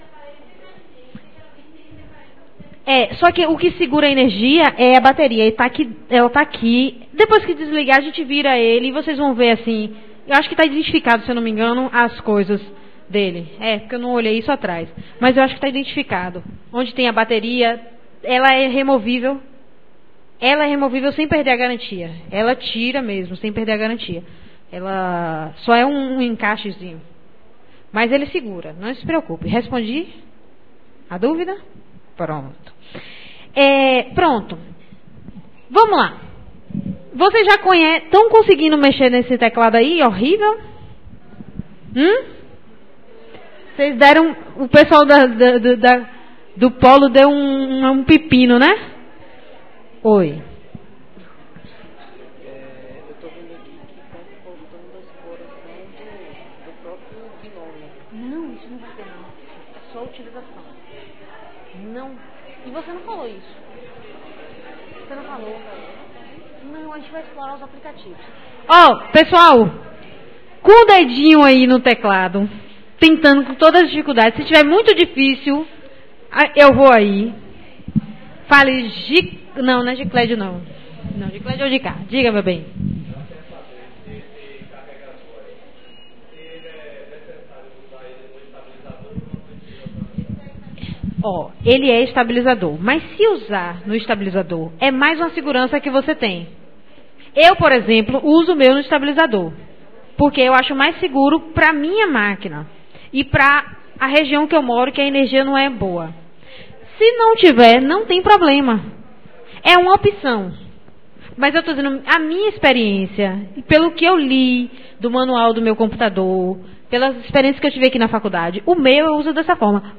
gravacao_transmissao_editado_menor_oficina_parte02.ogg